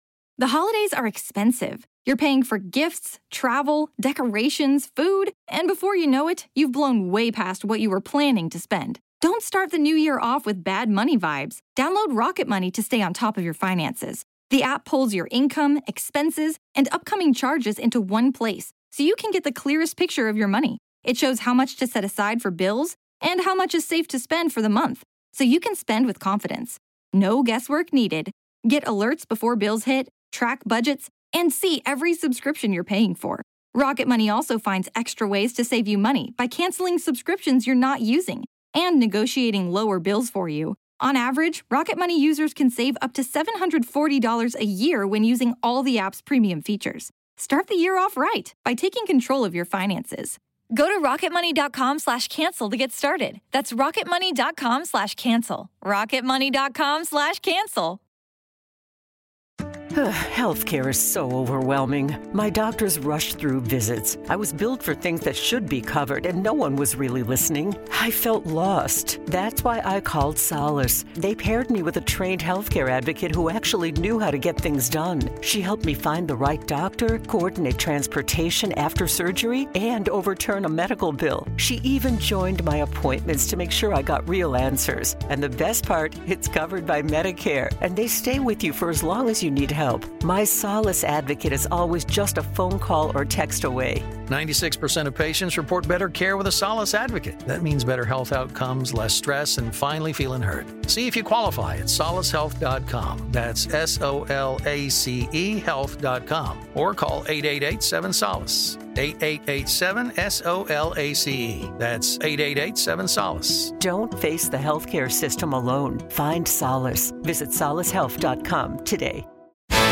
The governor of Pennsylvania, Josh Shapiro, sits down with Tim Miller in Philly.
The governor of Pennsylvania, Josh Shapiro, sits down with Tim Miller in Philly to talk about the kind of language that wins over voters, the campus protests, finding the sweet spot on public safety and crime, and getting sh*t done. show note: This interview was recorded late Wednesday afternoon.